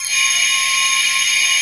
MECHANICBIRD.wav